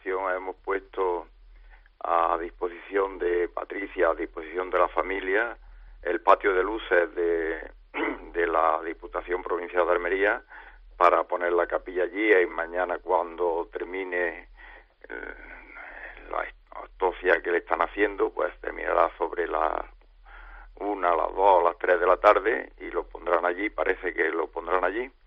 Escucha al presidente de la Diputación de Almería en 'La Noche' de COPE